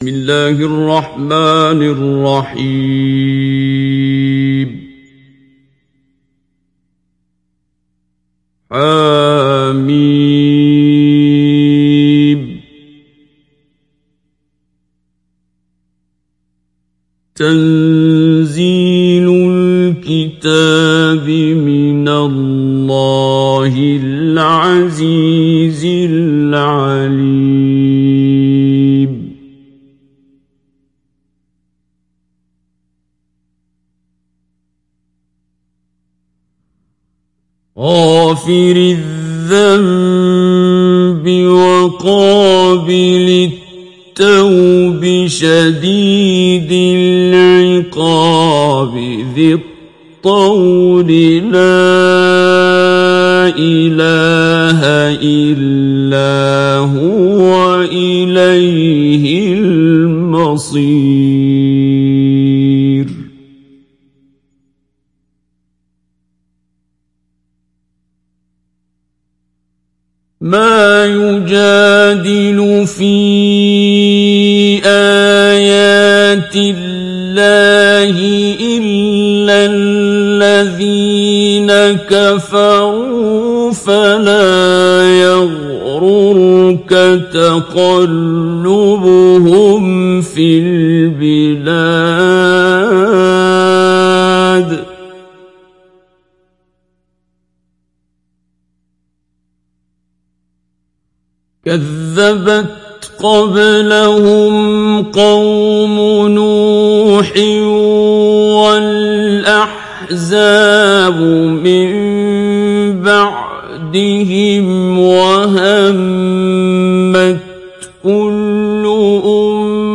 Surat Ghafir mp3 Download Abdul Basit Abd Alsamad Mujawwad (Riwayat Hafs)
Download Surat Ghafir Abdul Basit Abd Alsamad Mujawwad